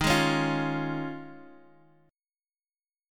Eb9sus4 chord